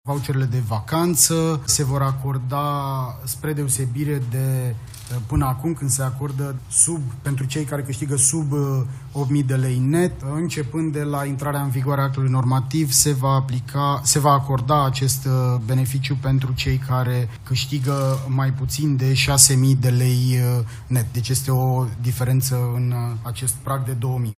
Prezentare maraton la Palatul Victoria: vicepremierul Tanczos Barna alături de miniștrii Finanțelor, Muncii, Sănătății și Educației au prezentat măsurile din pachetul fiscal pentru care Guvernul își va asuma răspunderea săptămâna viitoare.